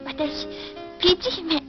This file is an audio rip from a(n) SNES game.